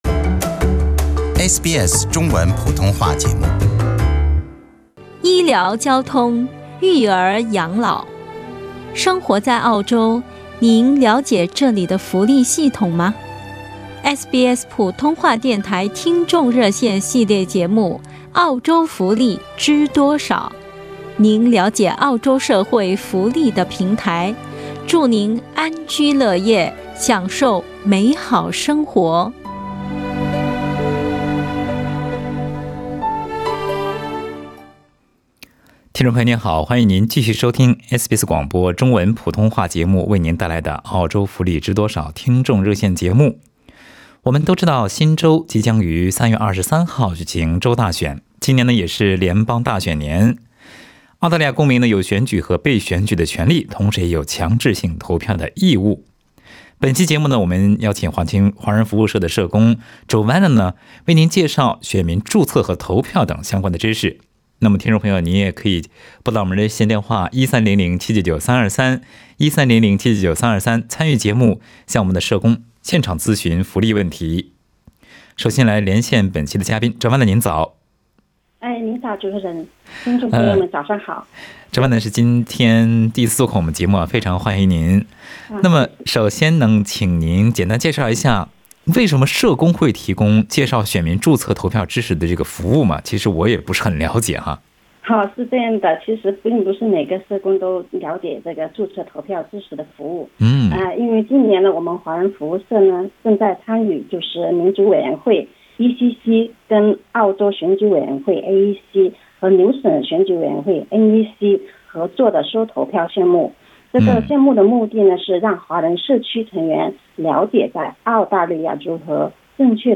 听众热线节目